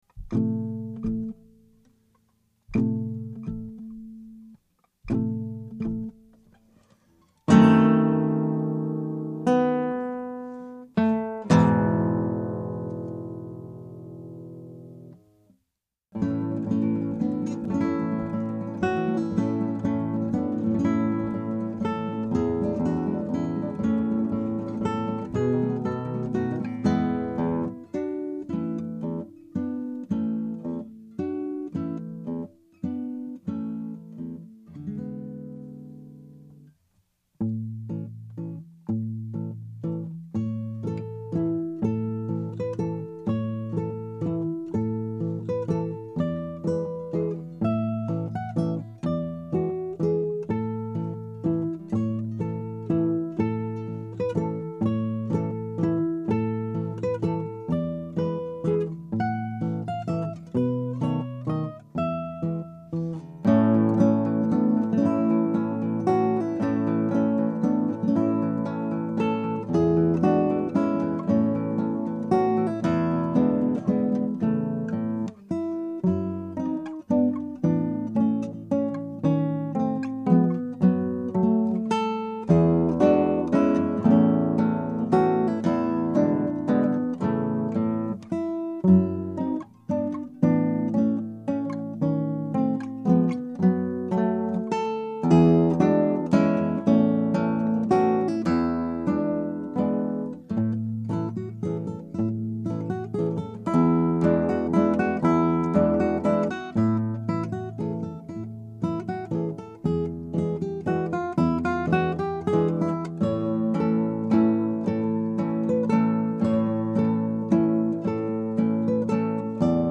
Scraps from the Operas arranged for Two Guitars
Scrap: Andantino.